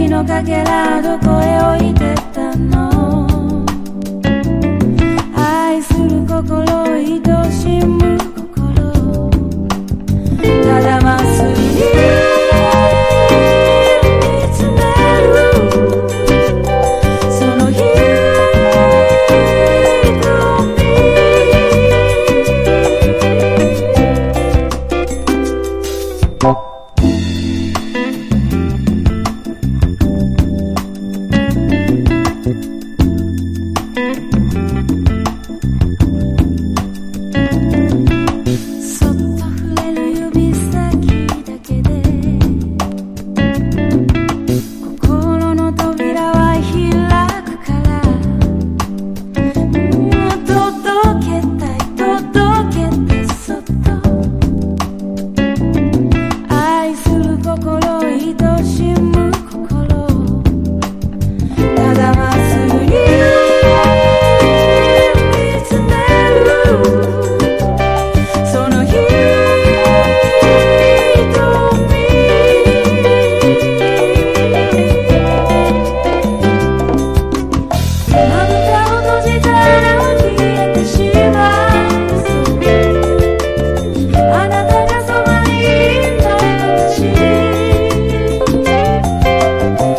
CITY POP / AOR# CLUB# REGGAE / SKA / DUB